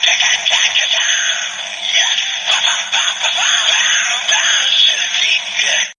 Gh_bangbang_shooting_l3.mp3